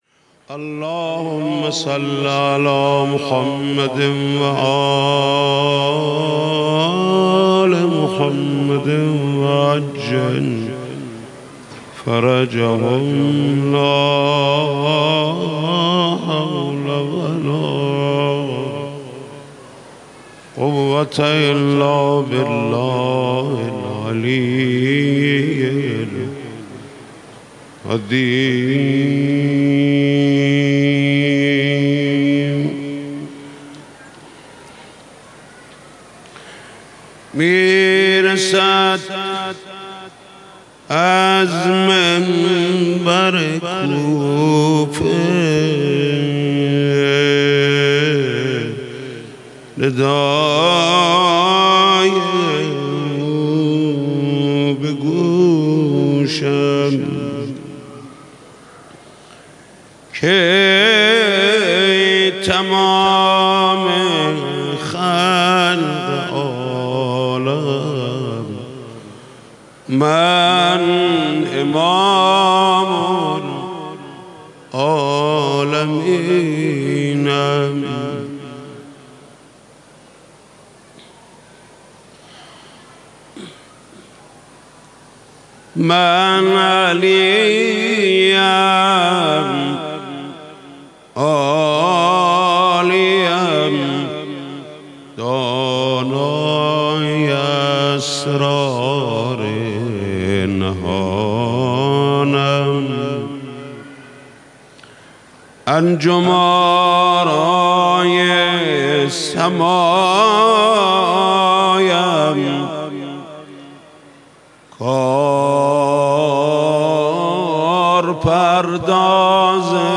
حاج محمود کریمی/گلچین مداحی های شب های ششم تا هشتم ماه مبارک رمضان۹۷/مشهد مقدس – مجله نودیها